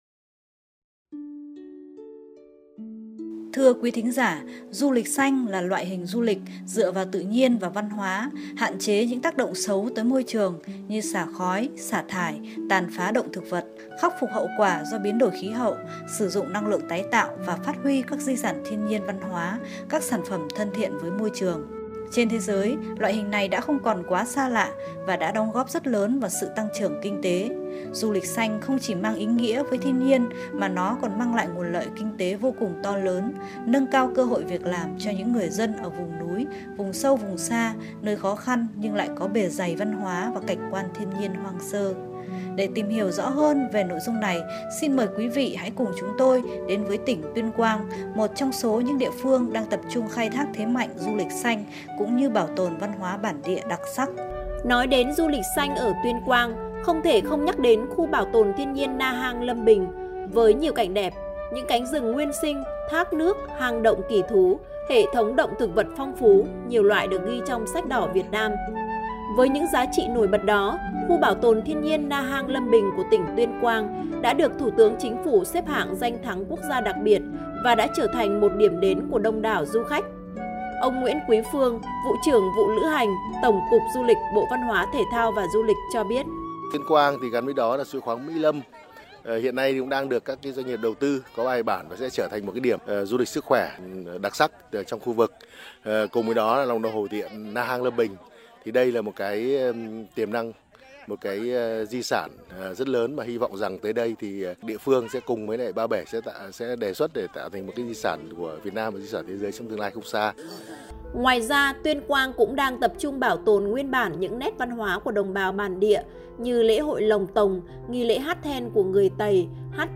File phát thanh